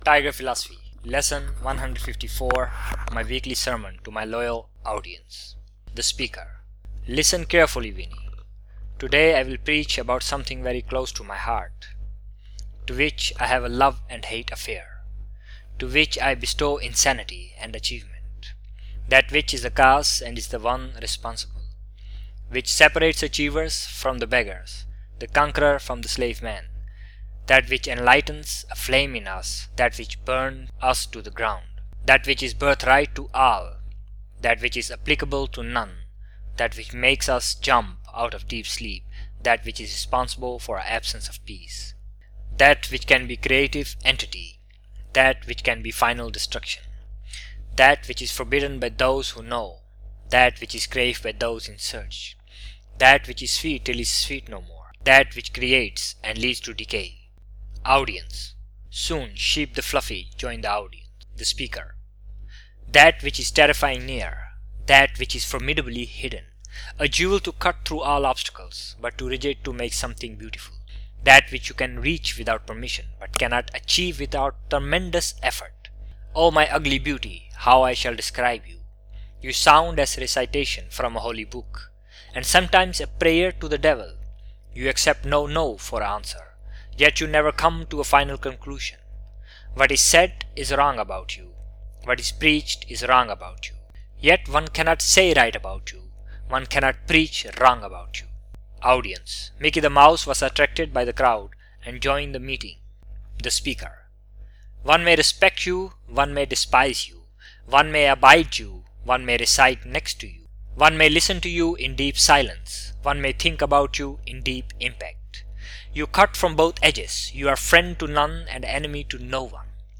Tiger Philosophy lesson 154: My weekly sermon to my loyal audience!